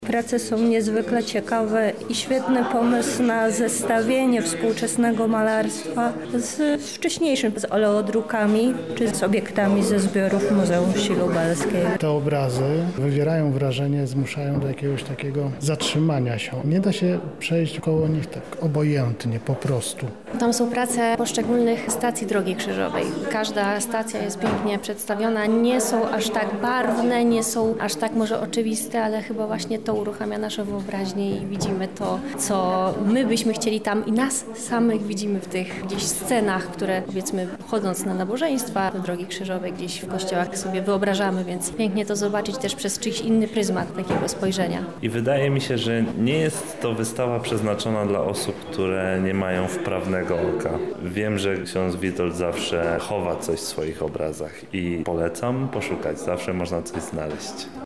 Zapytaliśmy zwiedzających o ich refleksje po obejrzeniu wystawy:
sonda